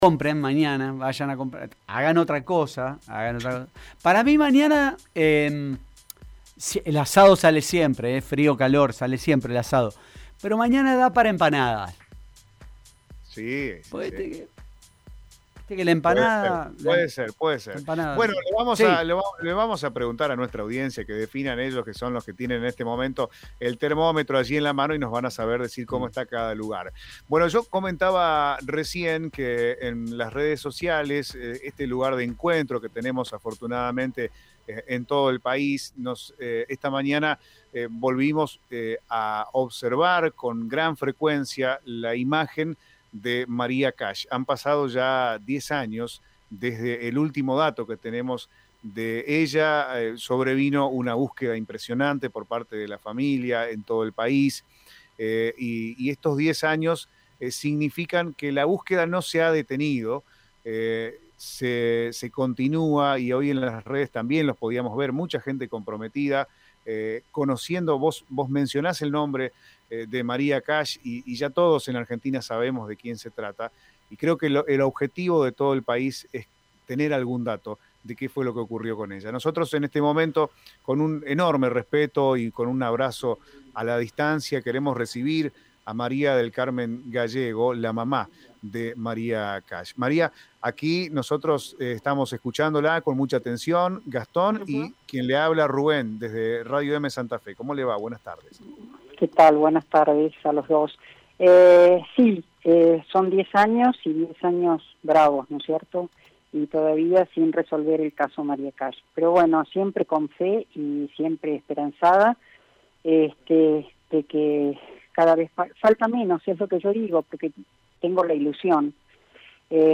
En diálogo con Radio Eme